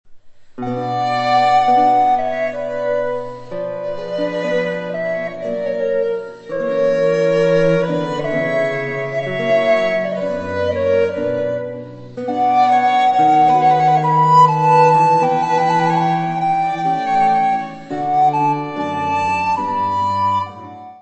flauta de bisel
violino
oboé
viola de gamba
alaúde
cravo
Área:  Música Clássica